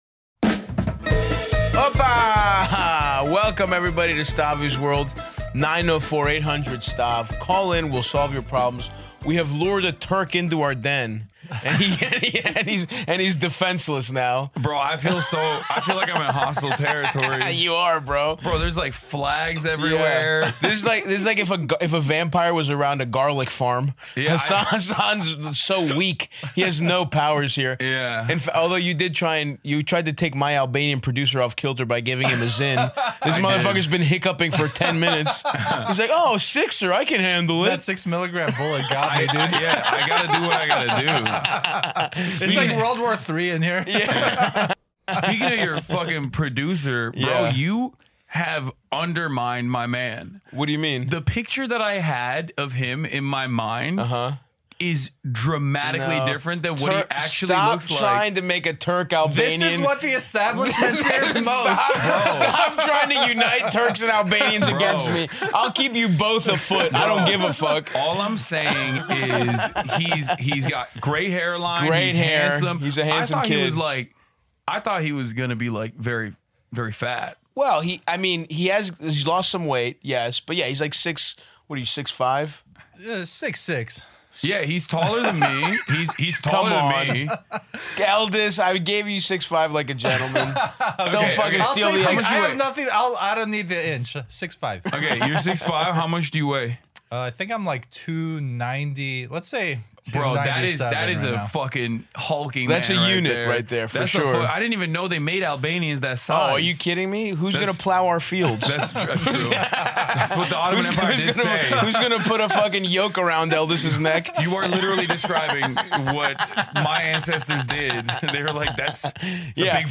Hasan Piker joins the podcast for the second installment of the LA Chronicles at the Bad Friends Studio to discuss Turkey vs. Greece, his true origins, his childhood fondness for basketball, the family call that led his dad to confront him, the daddy stitch, his stellar academic record, and much more.